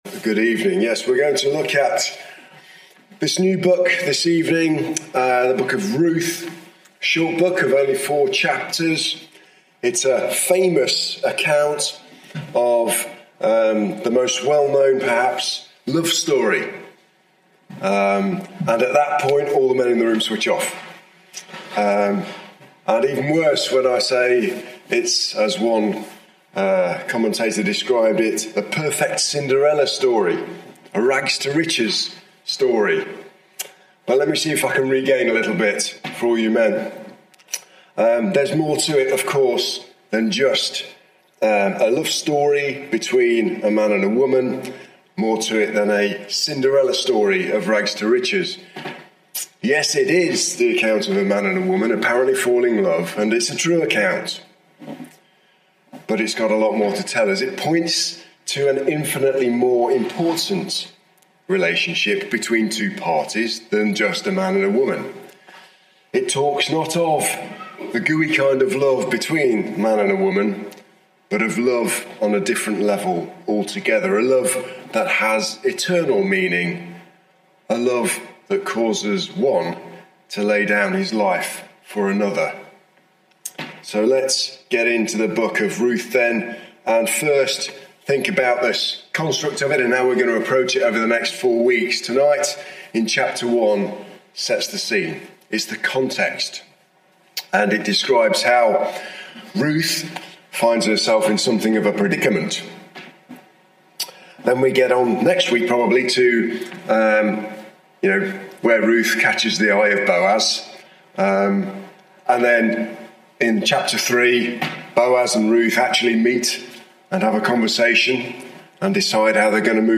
Share this: Share on X (Opens in new window) X Share on Facebook (Opens in new window) Facebook Share on WhatsApp (Opens in new window) WhatsApp Series: Sunday evening studies Tagged with Verse by verse